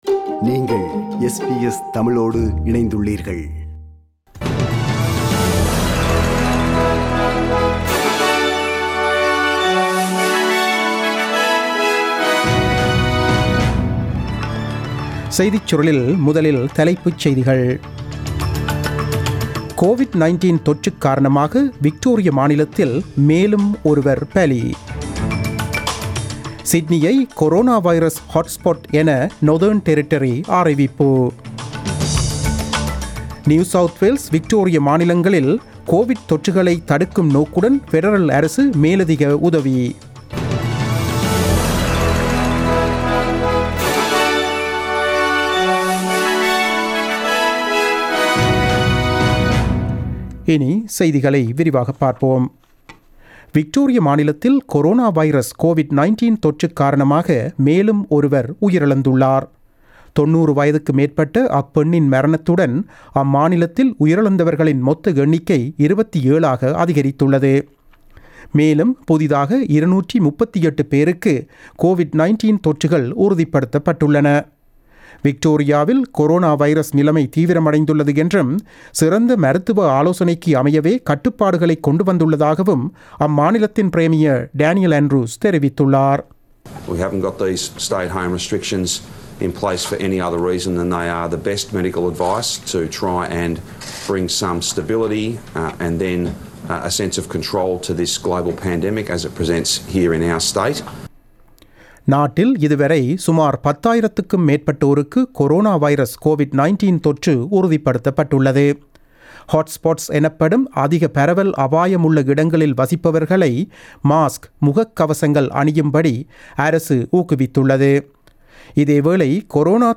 The news bulletin broadcasted on 15 July 2020 at 8pm.